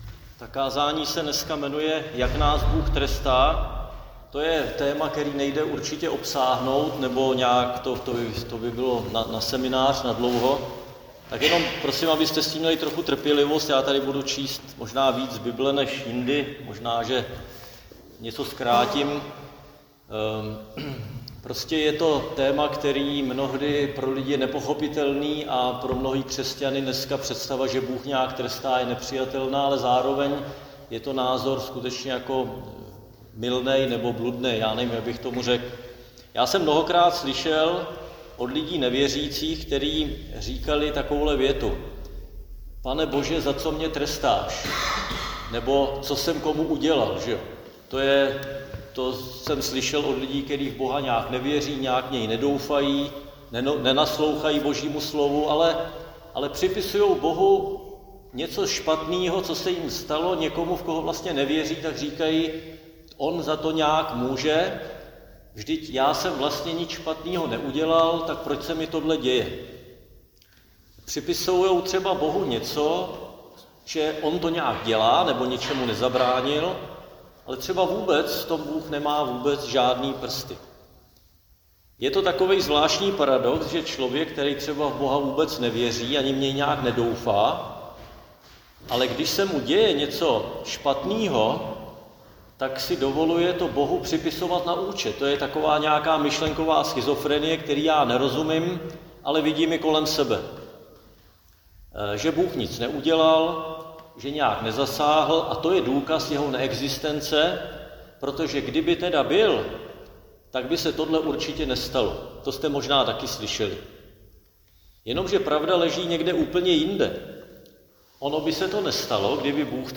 Křesťanské společenství Jičín - Kázání 2.11.2025